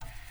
floppy_525_motor_start.wav